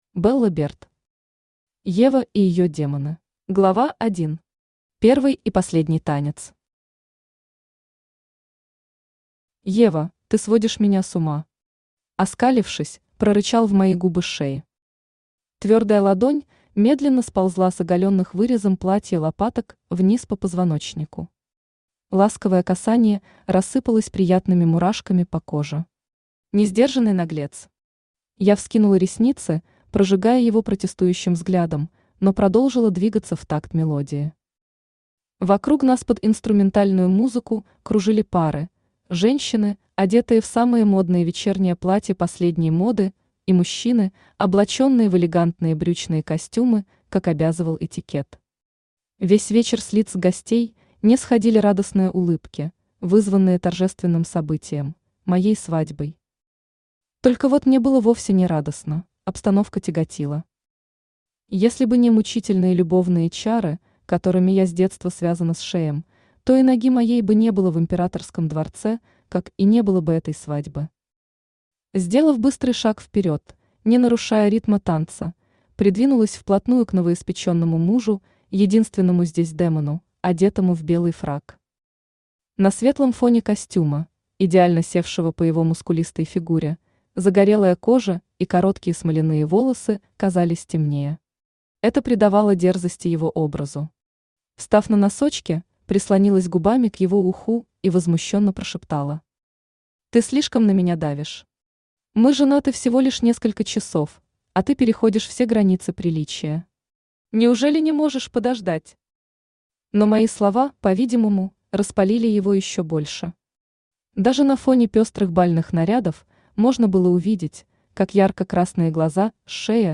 Aудиокнига Ева и ее демоны Автор Белла Берт Читает аудиокнигу Авточтец ЛитРес.